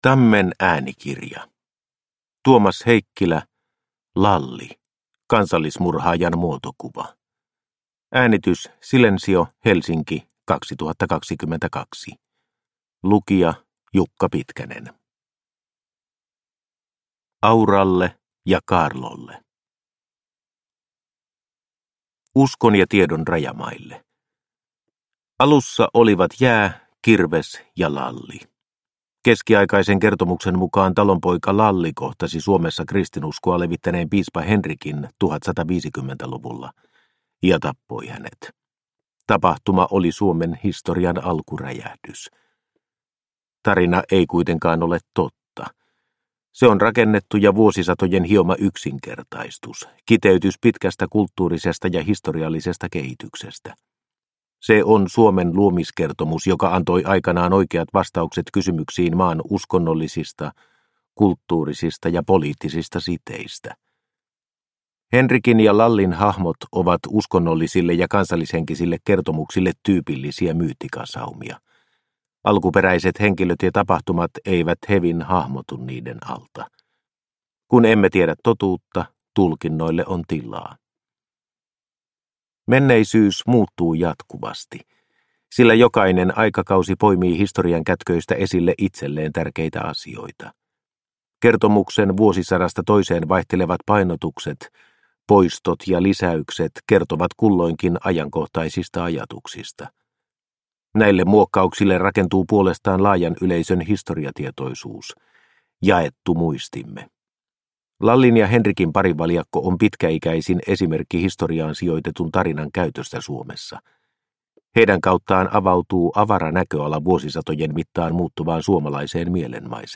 Lalli – Ljudbok – Laddas ner